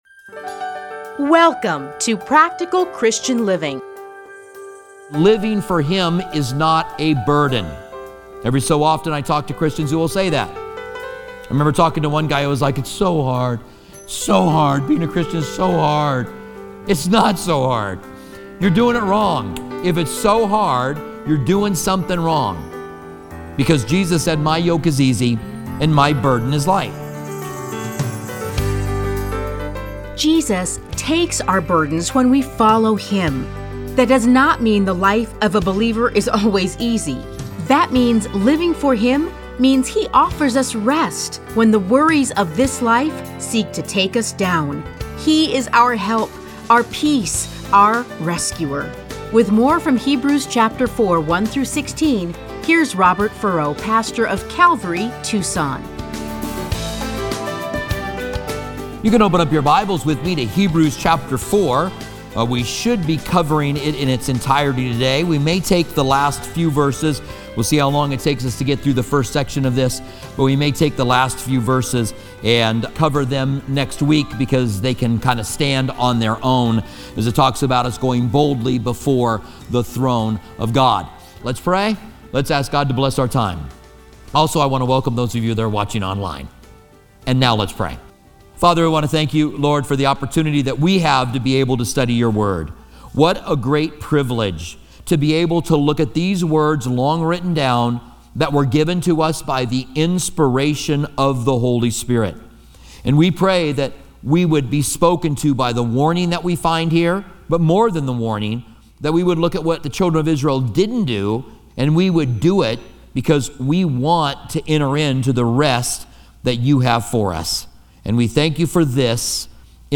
Listen to a teaching from Hebrews 4:1-16.